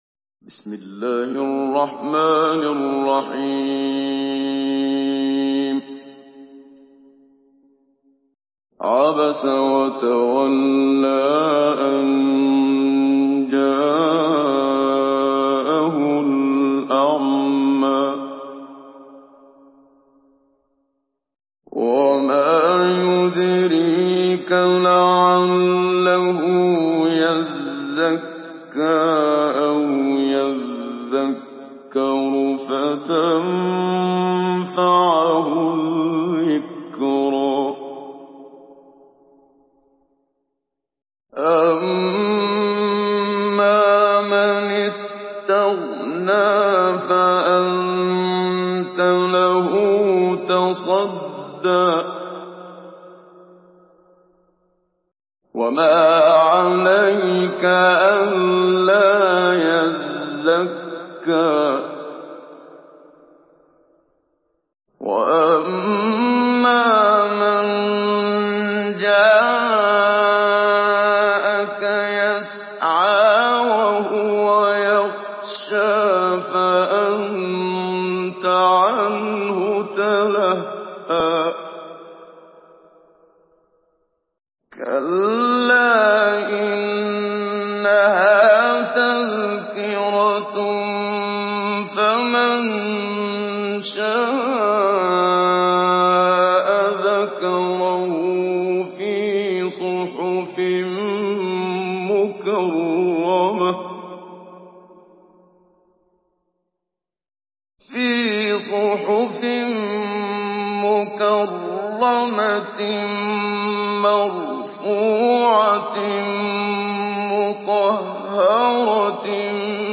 Surah عبس MP3 in the Voice of محمد صديق المنشاوي مجود in حفص Narration
Surah عبس MP3 by محمد صديق المنشاوي مجود in حفص عن عاصم narration.